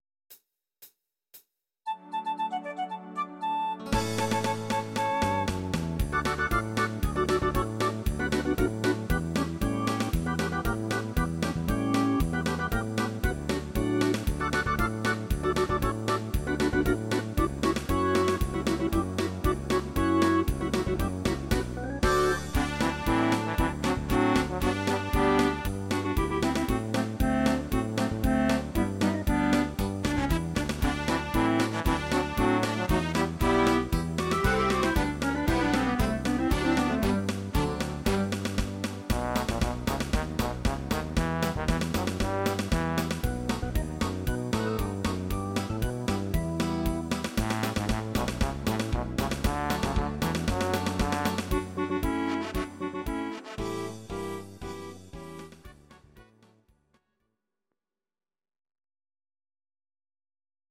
Audio Recordings based on Midi-files
Jazz/Big Band, Instrumental